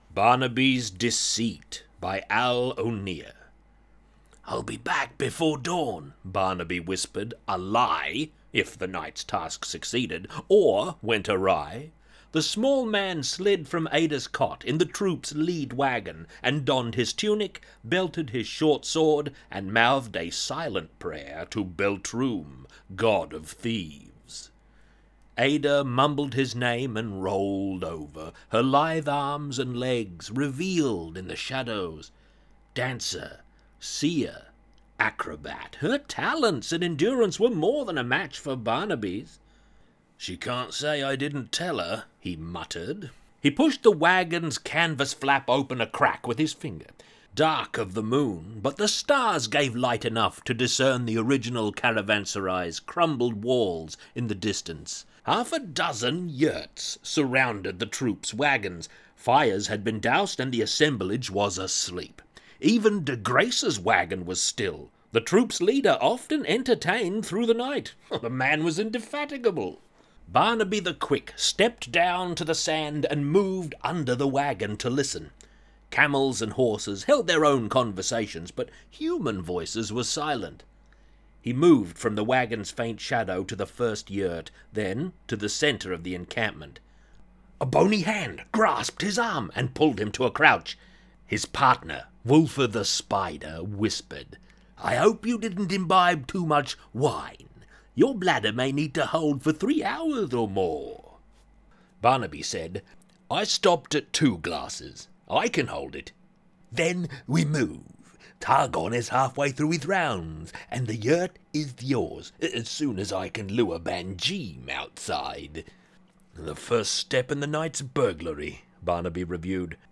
Barnabys-Deceit-Reading.mp3